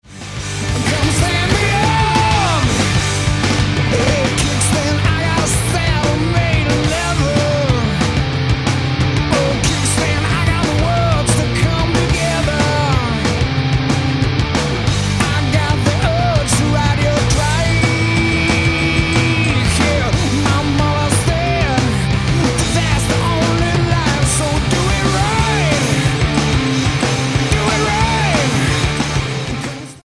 voc, gtr
drums
bass